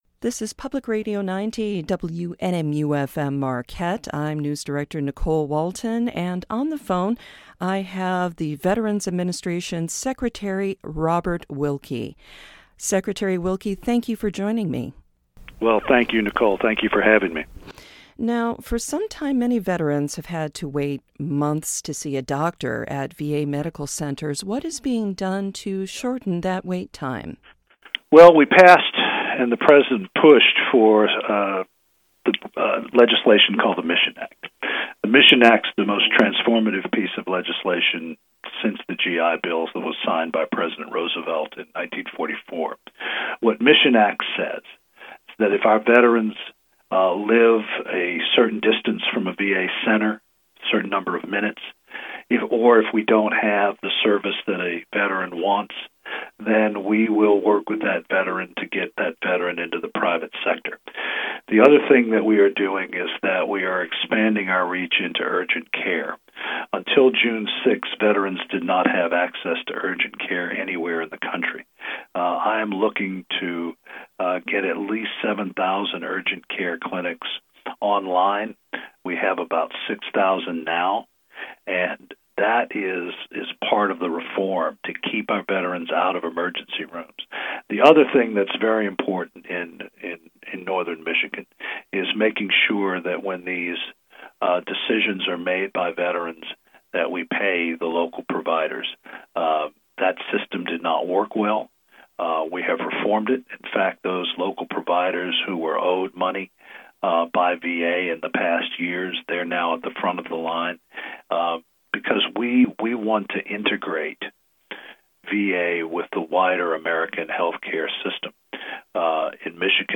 What's going on in the VA: an interview with Secretary Robert Wilkie